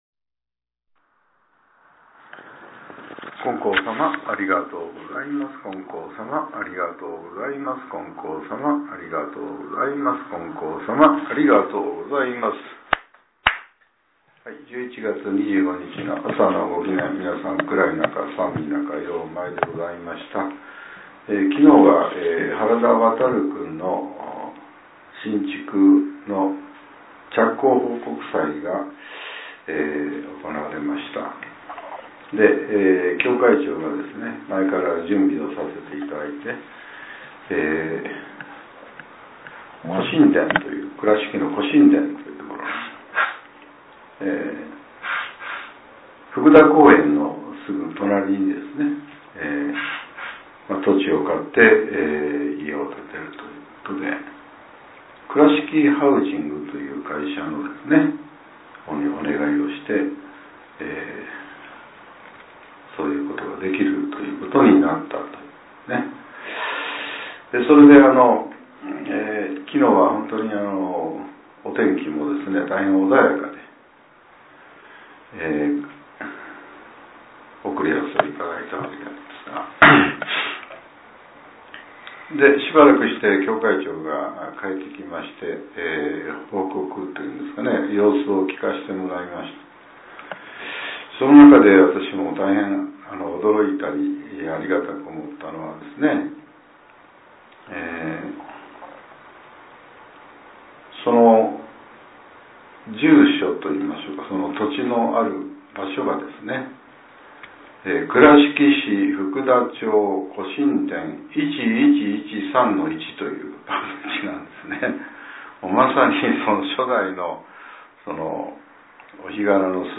令和７年１１月２５日（朝）のお話が、音声ブログとして更新させれています。